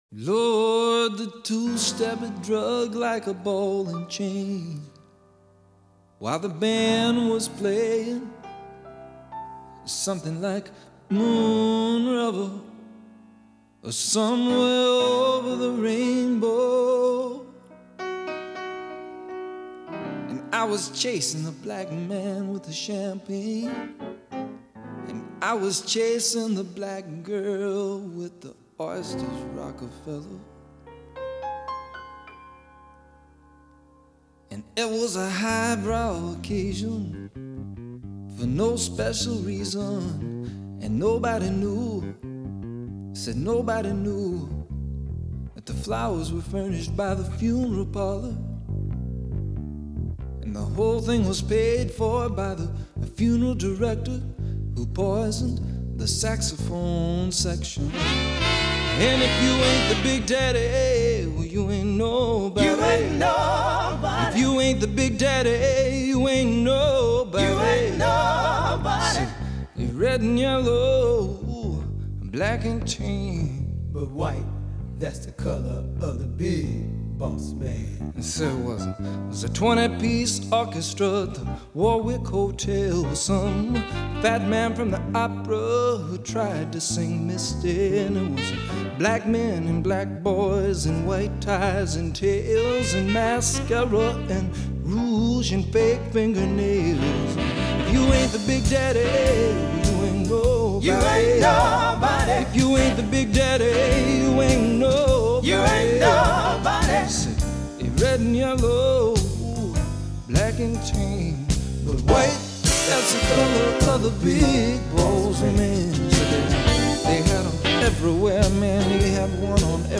an enjoyable understated performance.